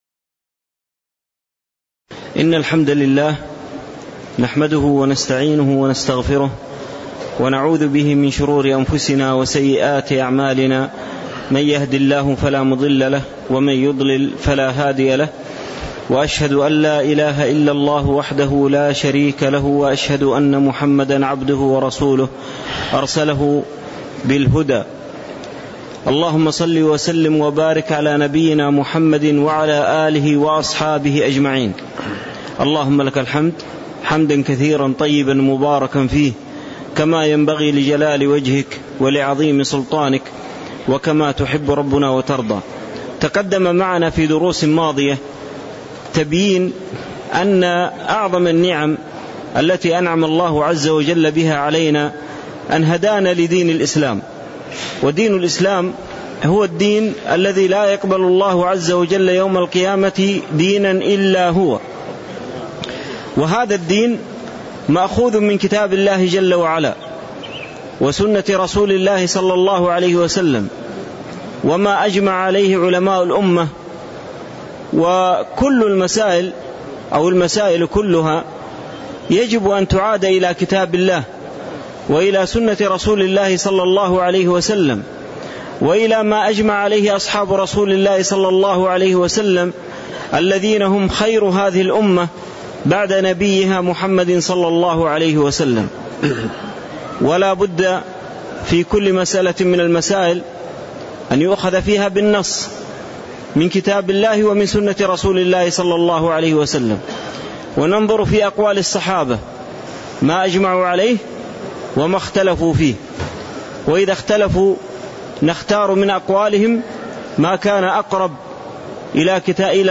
تاريخ النشر ٢٤ ذو القعدة ١٤٣٧ هـ المكان: المسجد النبوي الشيخ